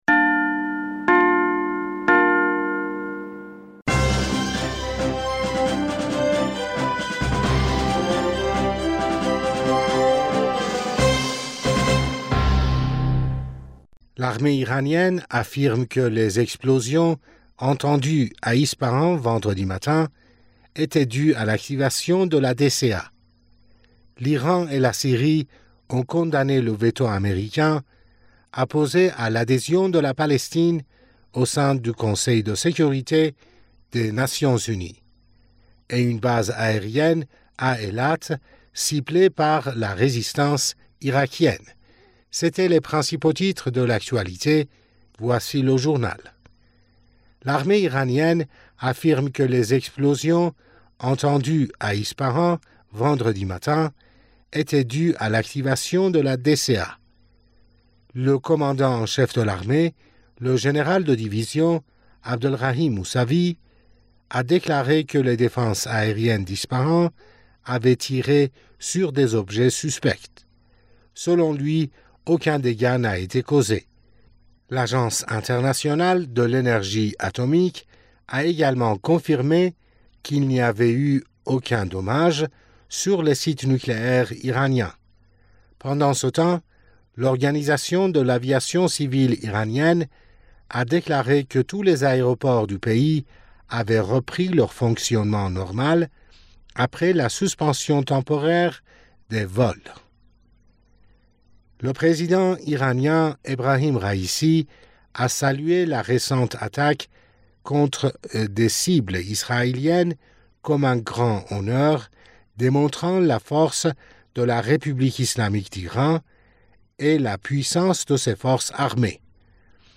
Bulletin d'information du 20 Avril